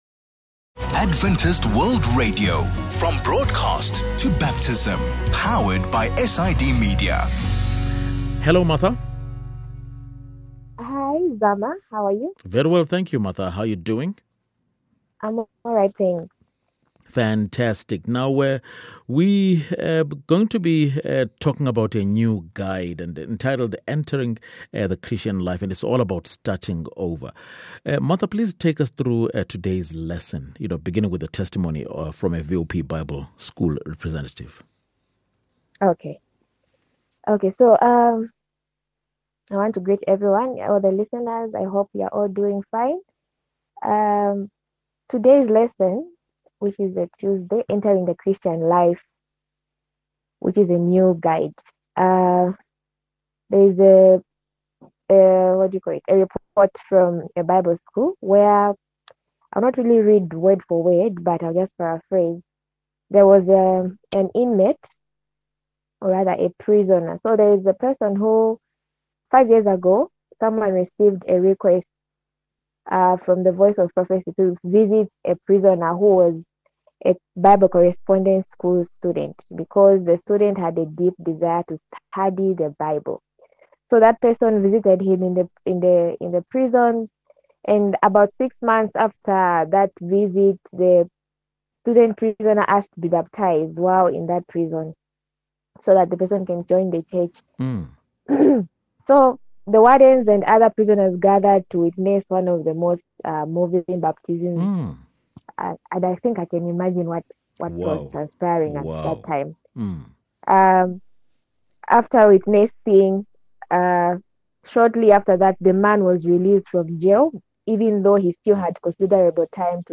8 Oct VOP Lesson | Entering the Christian Life